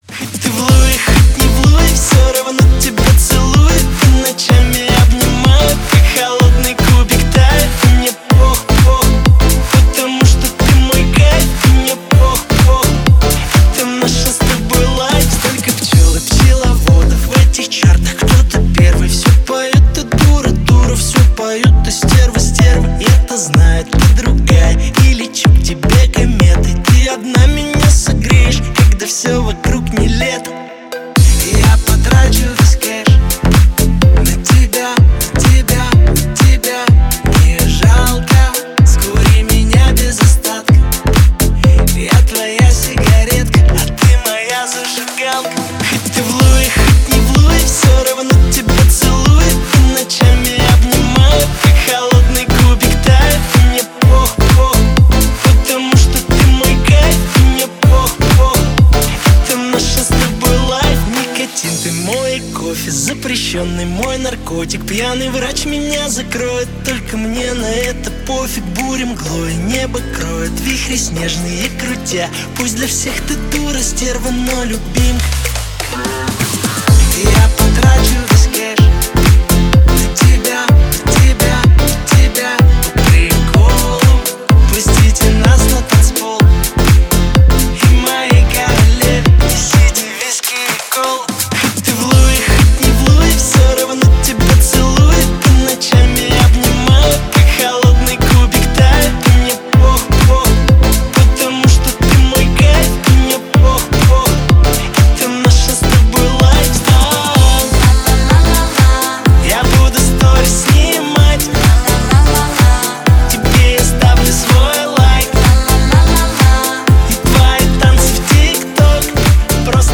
звучат захватывающие мелодии и динамичные переходы